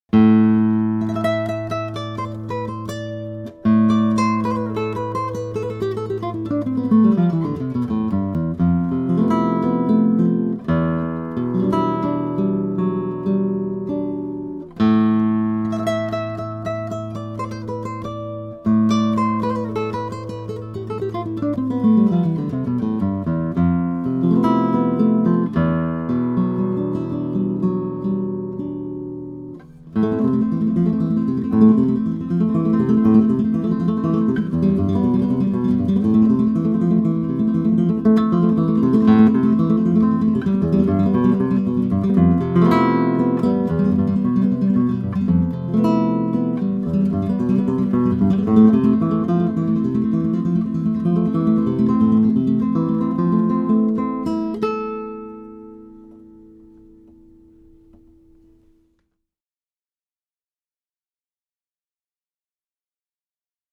This Double Back employs a second ‘internal body’ that resonates free of any restriction from the players body. Sound emanates not only from soundhole but from the entire perimeter of the top.